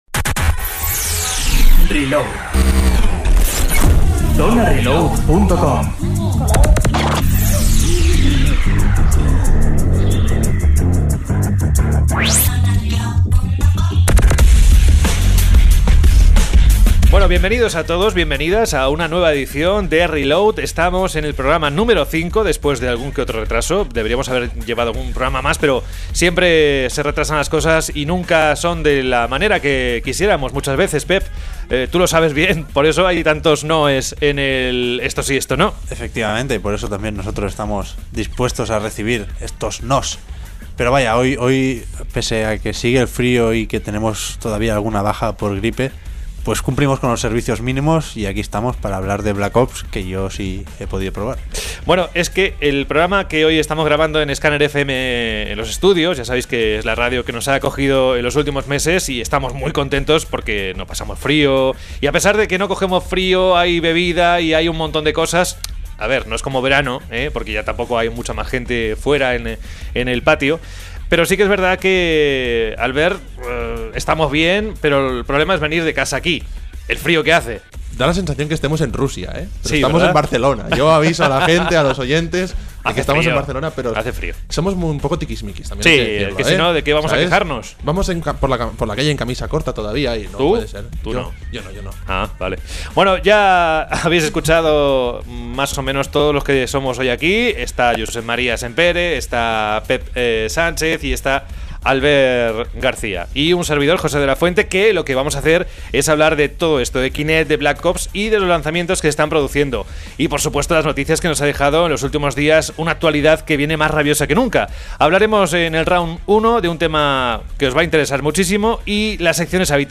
Reload se graba en los estudios de Scanner FM , una radio alternativa que seguro que os gustará y que podéis escuchar por Internet.